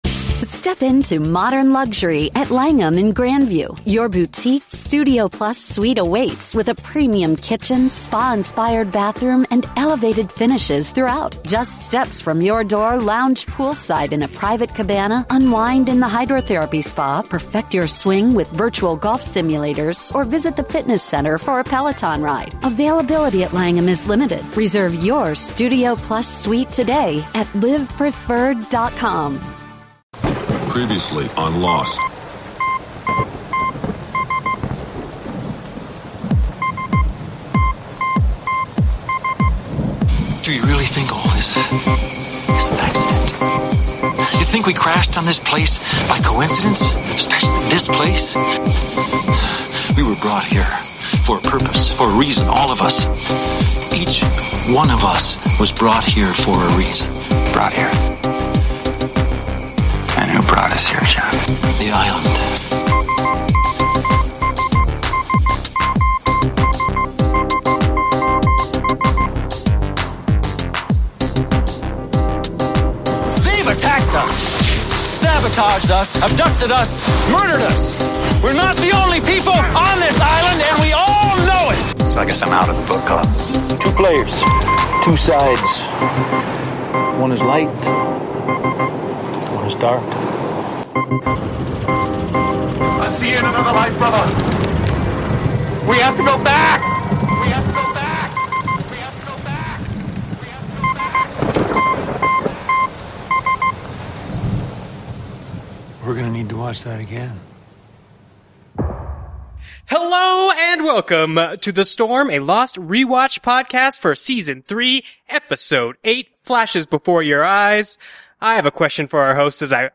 29 seconds THE INTERVIEW